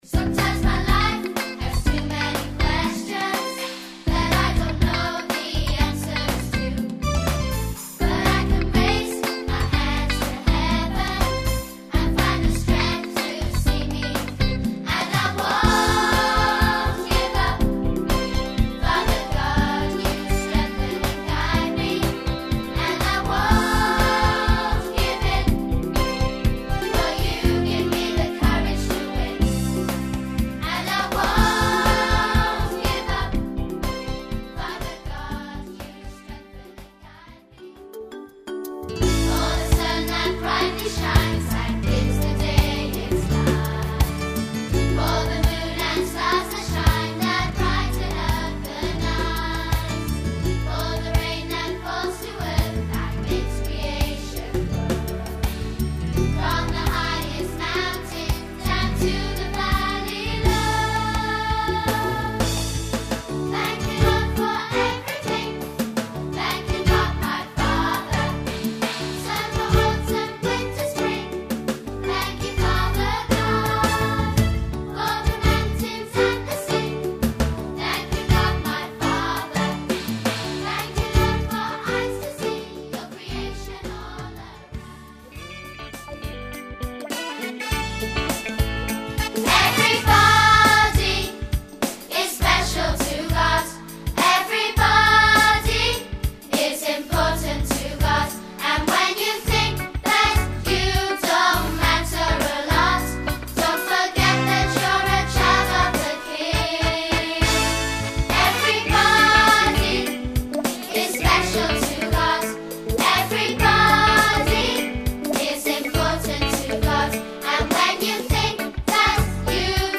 assembly praise songs